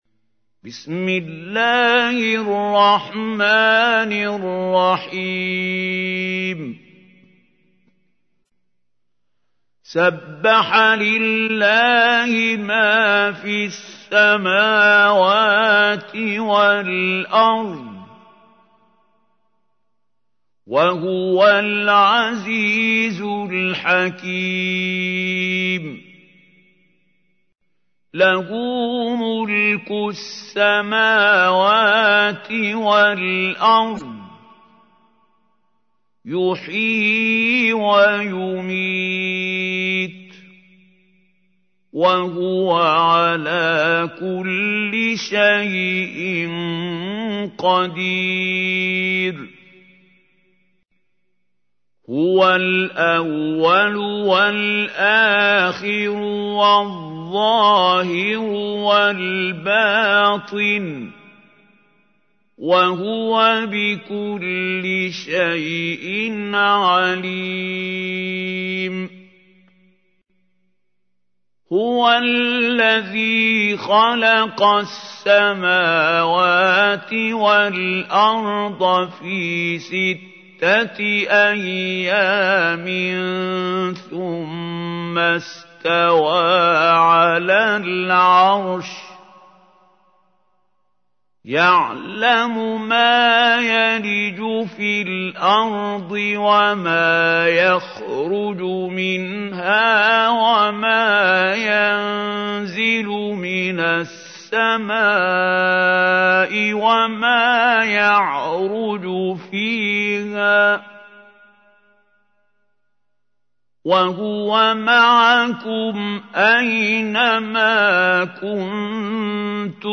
تحميل : 57. سورة الحديد / القارئ محمود خليل الحصري / القرآن الكريم / موقع يا حسين